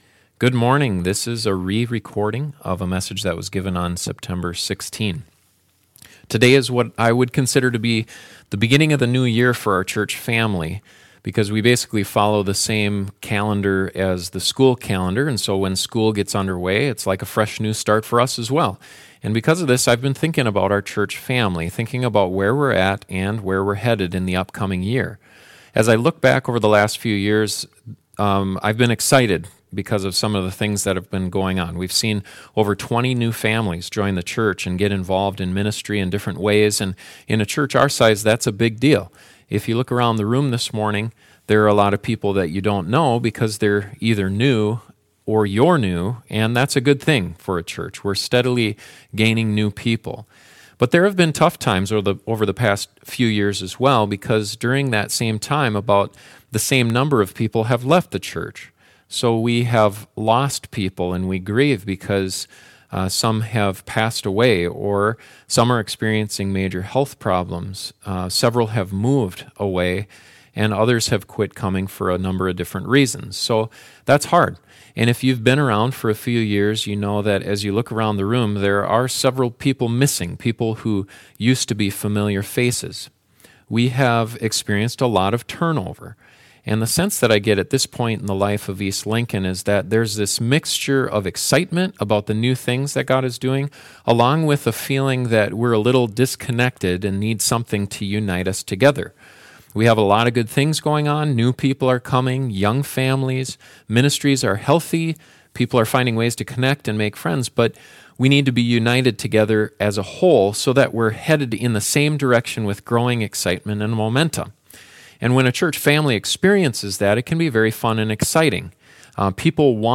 In this introductory sermon to the book of Acts we begin to see God’s vision for the local church and three things he wants churches to focus on.